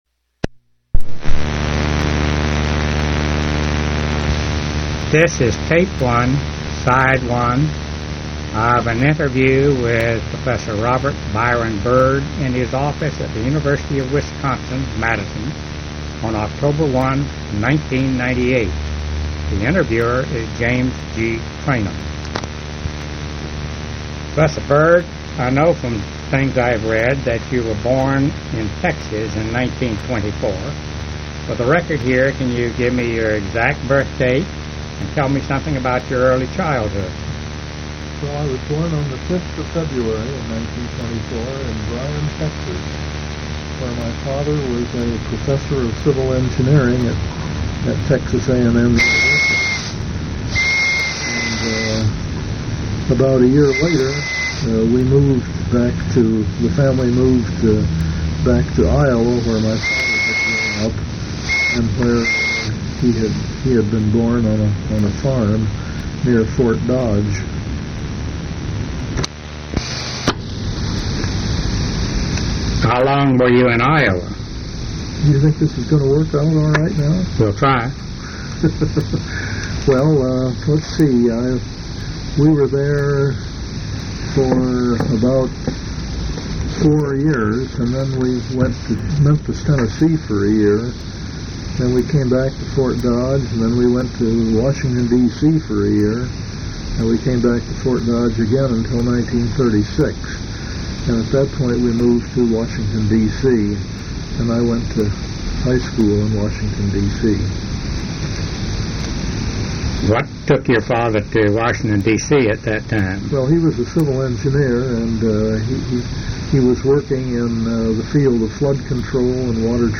Oral history interview with R. Byron Bird